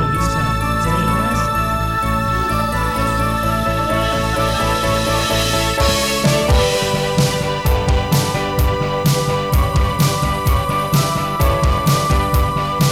Progressive Instrumentals recorded direct to digital